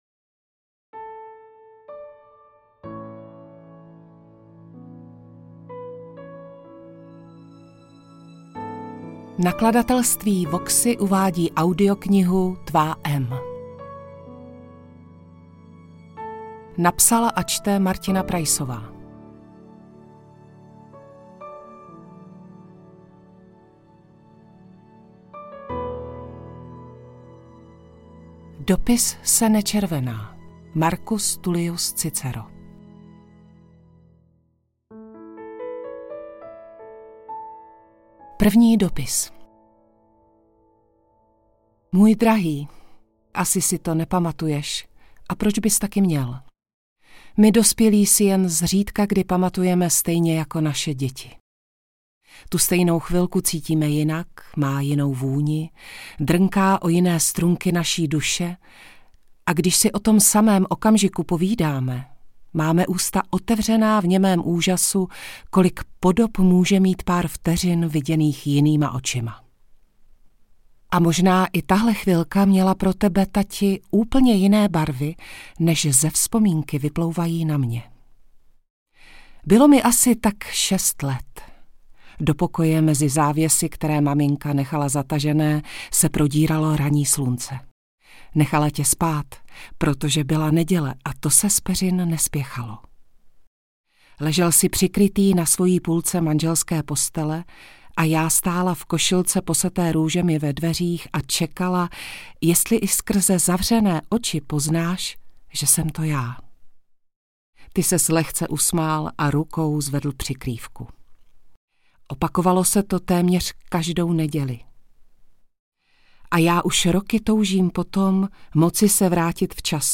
Audiobook
Read: Martina Preissová